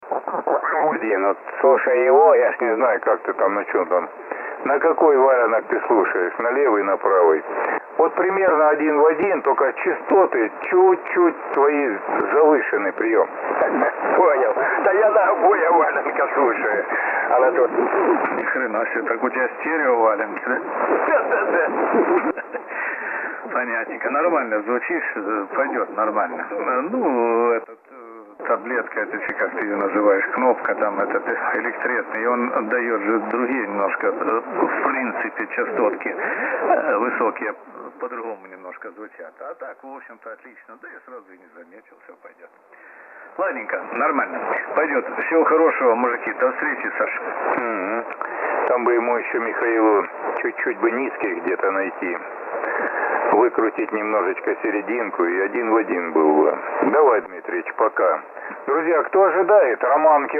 В следующей записи, сделал при включенном PRE и RF=5.
Ну не знаю, на мои уши ничего не давило и не било.
Не +50 но все таки...На записи слышно, как отключал/включал PRE, регулировал RF. АРУ отрабатывало.
7MHzssbpre.mp3